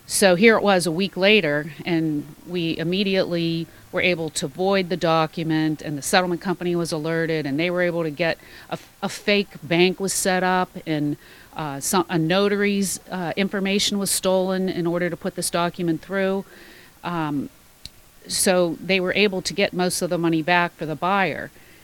In an interview on Indiana in the Morning on Tuesday, Indiana County Recorder of Deeds Maria Jack said that first of this string of scams was reported on November 21st.